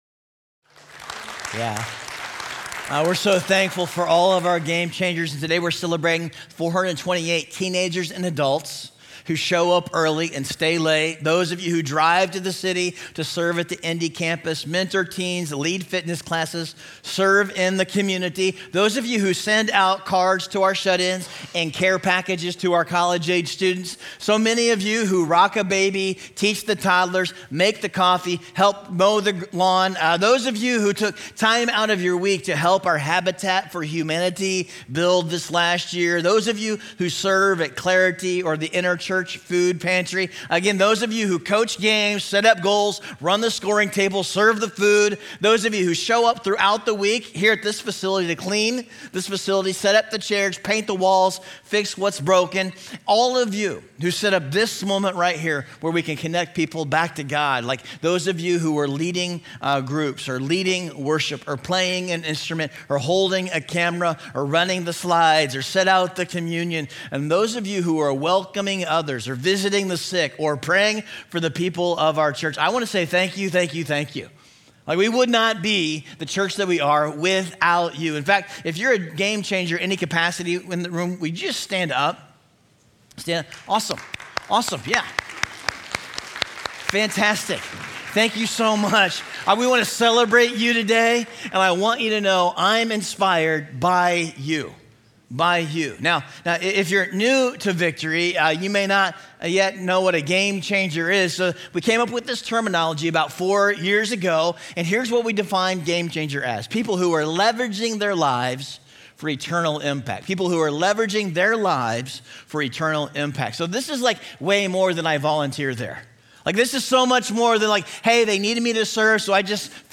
Gamechanger_Sunday_2025_Message_Audio.mp3